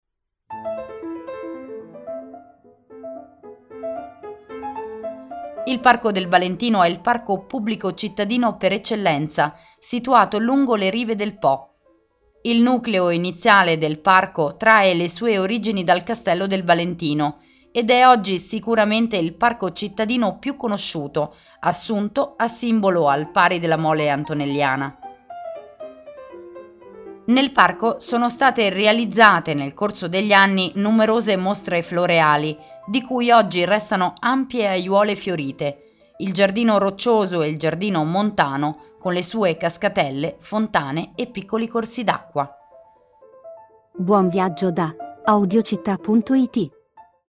Audioguida Torino – Parco del Valentino